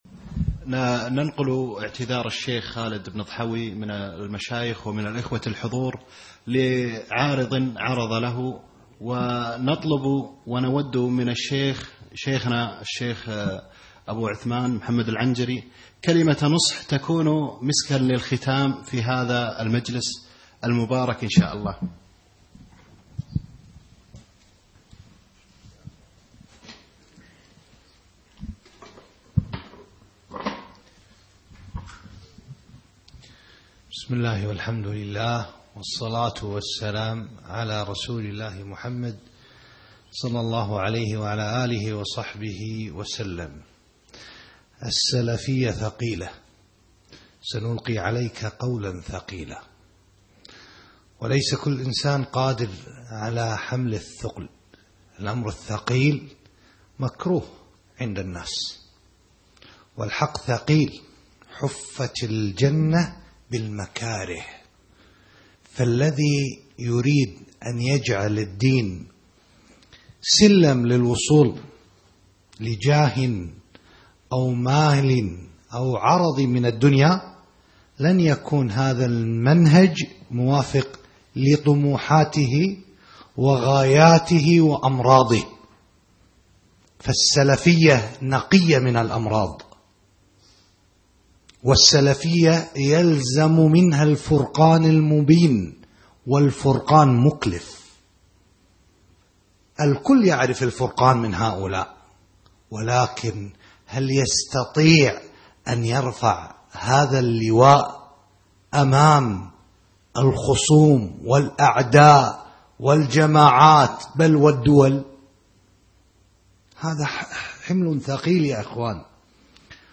السلفية ثقيلة - كلمة مؤثرة